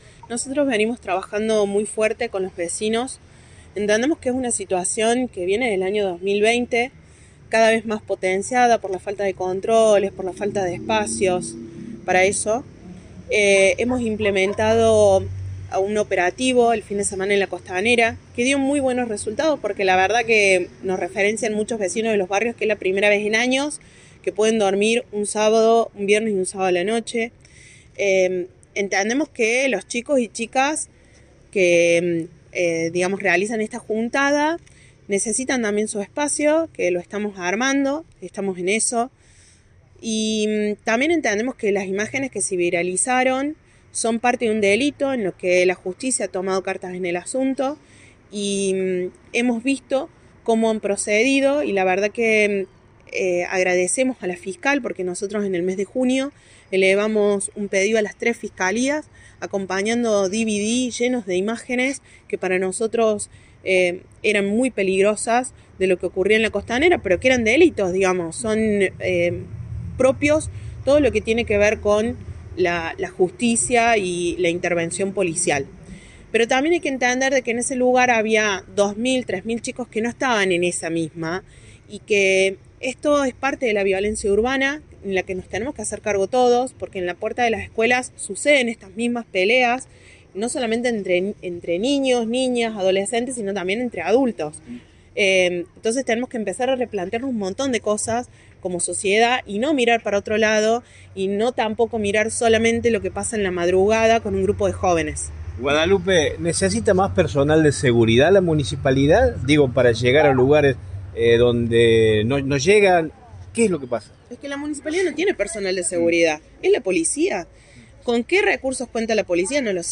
La secretaria de Seguridad del Municipio, Guadalupe Vázquez, brindó una amplia conferencia de prensa tras los hechos sucedido en la costanera -los videos virales de peleas y de un vehículo haciendo trompos- y se refirió a la cuestión de la seguridad, los reclamos vecinales y las responsabilidades en la materia.